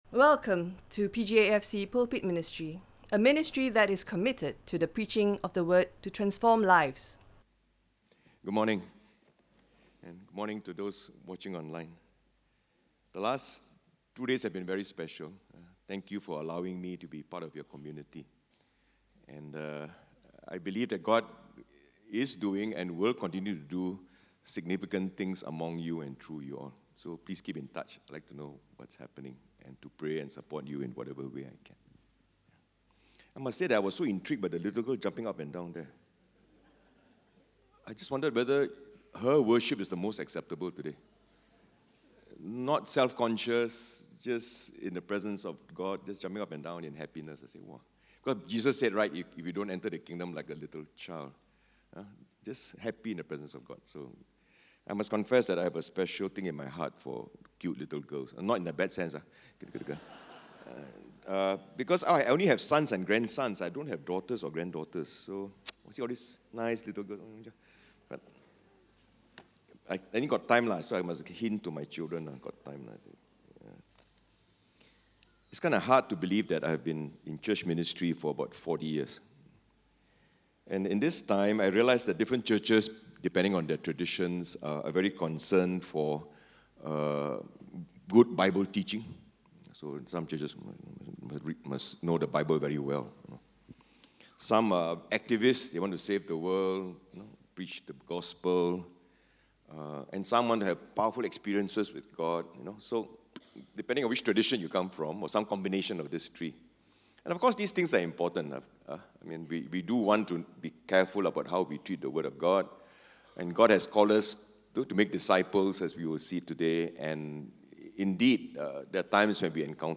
In conjunction with Disciple Making Conference, this is a stand alone sermon.